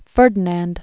(fûrdn-ănd) 1503-1564.